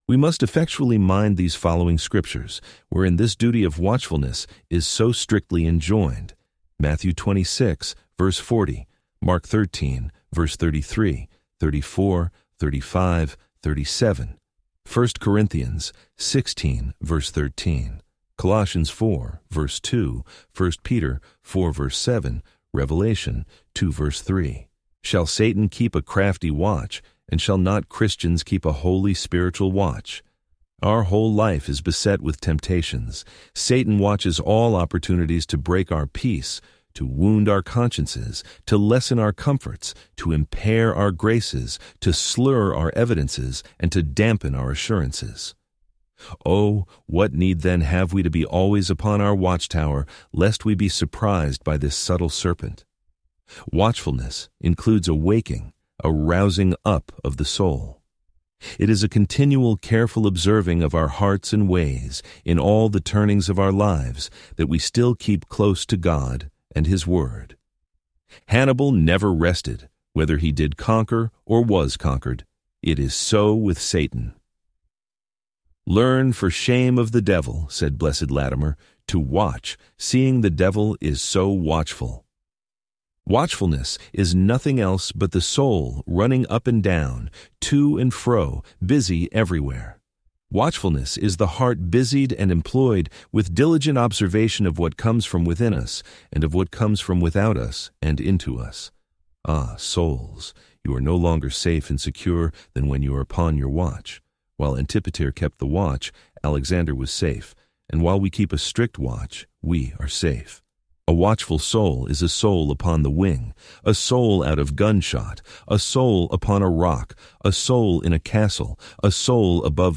Complete Audiobook